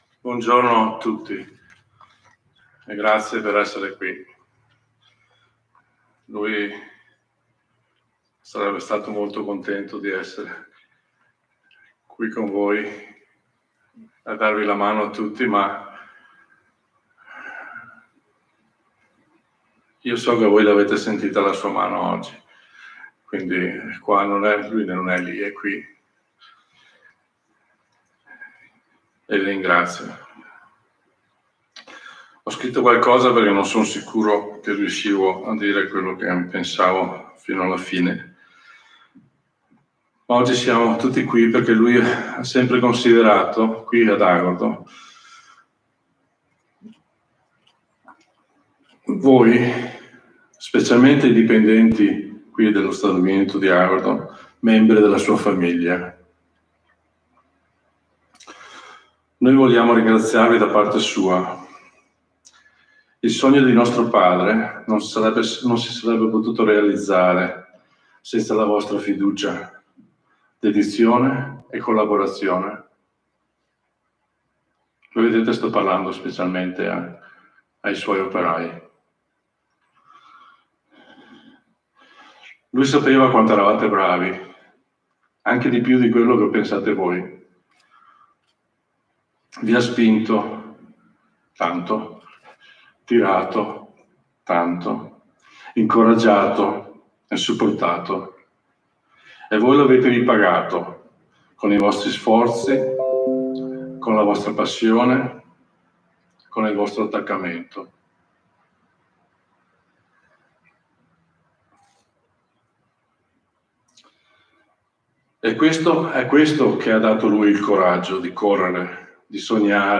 GLI INTERVENTI DEI FAMIGLIARI DI LEONARDO DEL VECCHIO PRIMA DEL FUNERALE E LE ULTIME PAROLE DEL CAVALIERE AL FIGLIO CLAUDIO
REDAZIONE Tra i momenti più toccanti del funerale di Leonardo Del Vecchio sicuramente le parole dei famigliari che hanno preso parola prima del rito funebre.